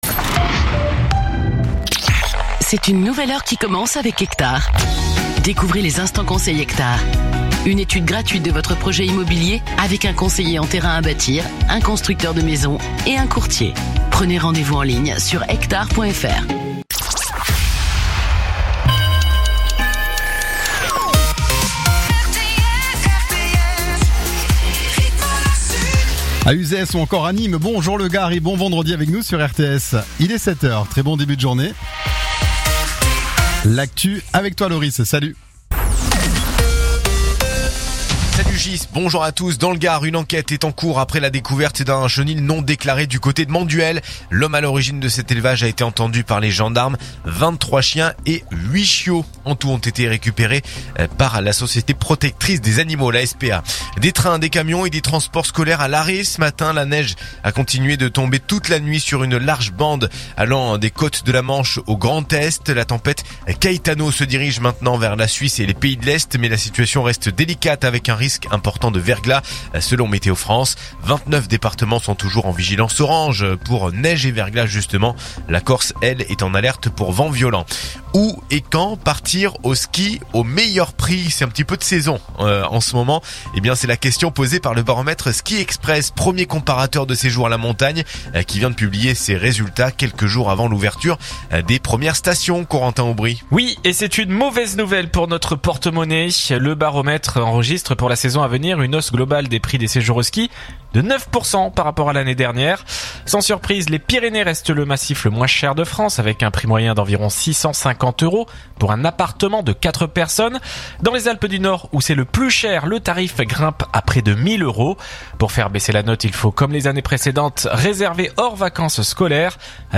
info_nimes_210.mp3